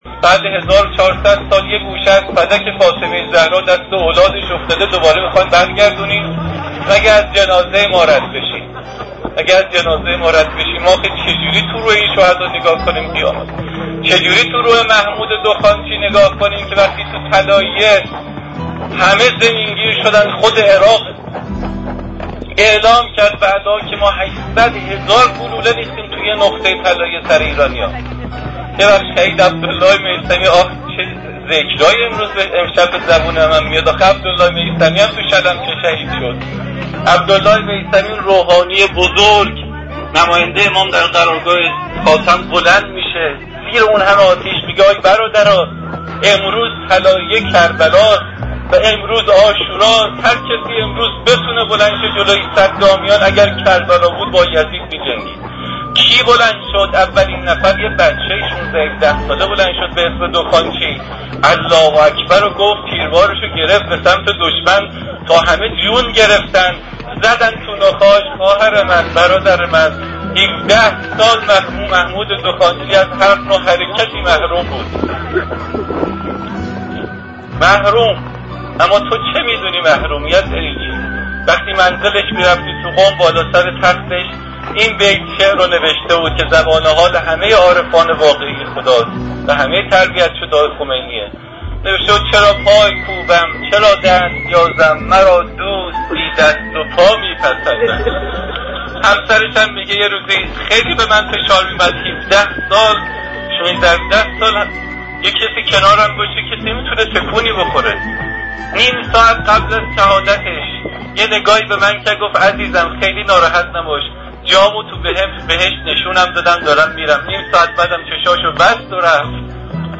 صوت روایتگری
ravayatgari194.mp3